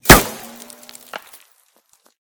/ gamedata / sounds / material / bullet / collide / dirt03gr.ogg 31 KiB (Stored with Git LFS) Raw History